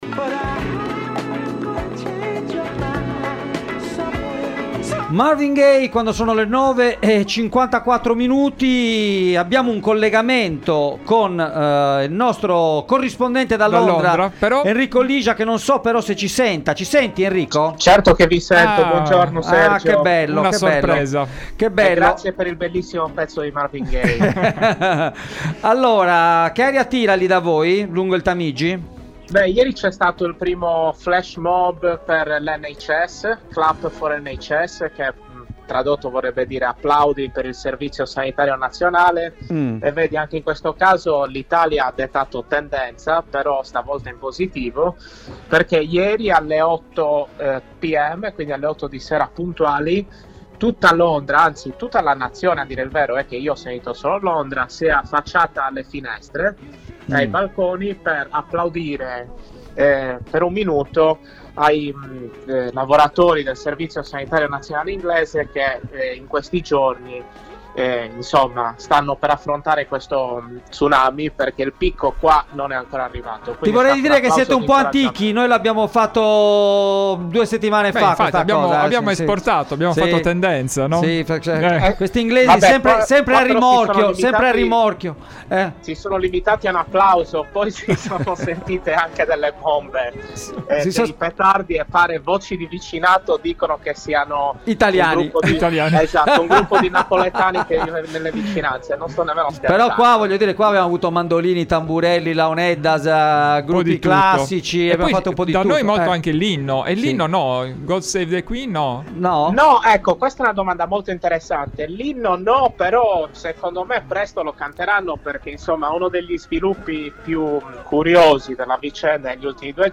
In diretta da Londra